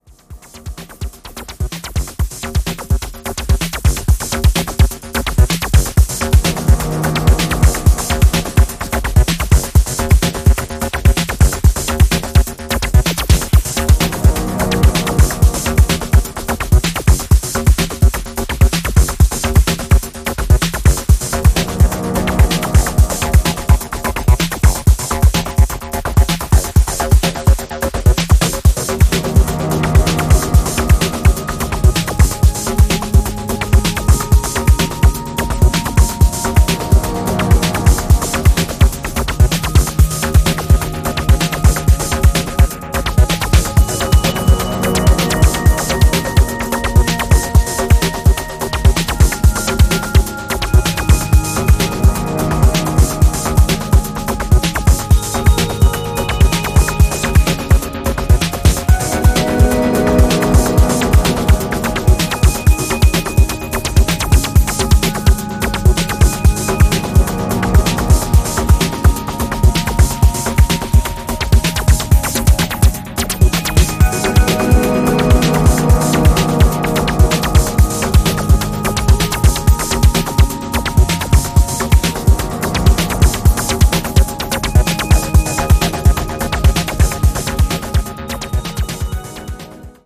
メランコリックなコズミック・エレクトロ
デトロイト古参ファンから新規ファンまで幅広く届いて欲しい、オールドスクール愛溢れる作品です。